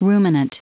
Prononciation du mot ruminant en anglais (fichier audio)
ruminant.wav